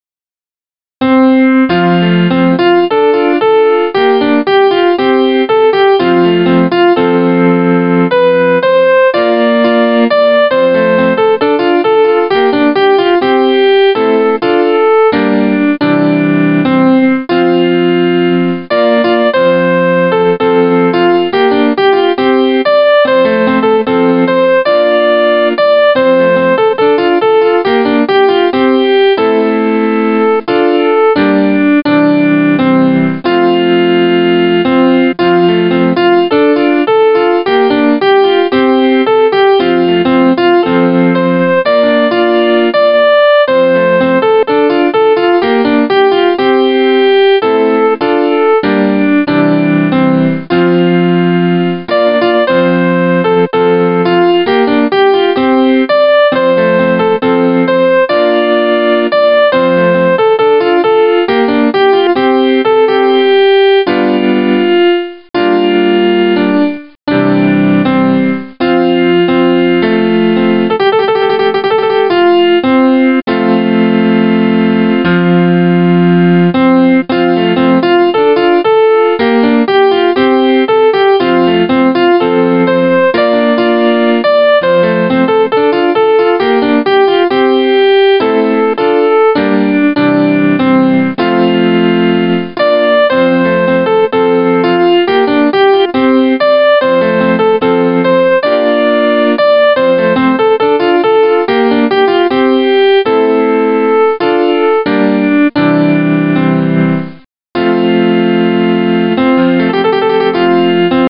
Voice used: Grand piano
Tempo: Andante Origin: Scottish